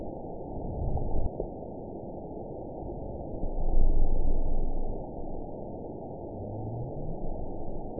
event 920156 date 03/01/24 time 08:53:05 GMT (1 year, 2 months ago) score 9.53 location TSS-AB05 detected by nrw target species NRW annotations +NRW Spectrogram: Frequency (kHz) vs. Time (s) audio not available .wav